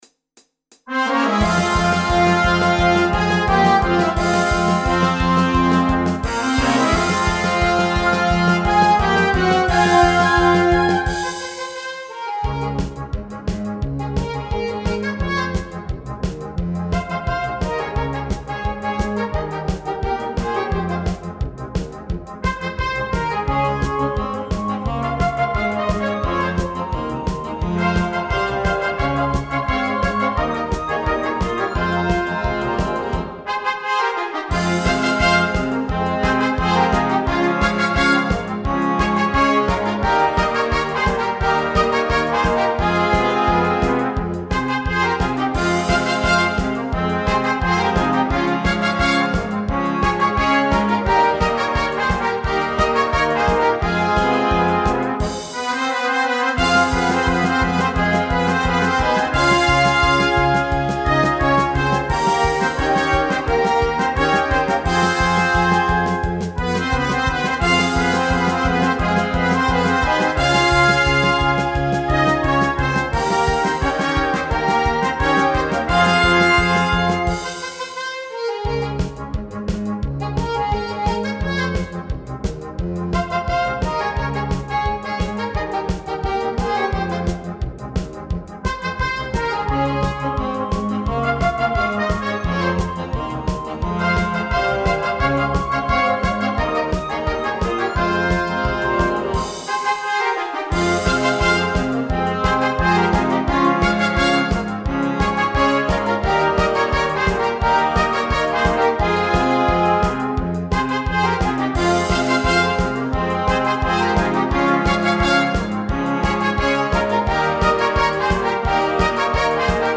Catégorie : Arrangement musical
Type de formation : Fanfare / Harmonie / Banda
Pré-écoute non téléchargeable · qualité réduite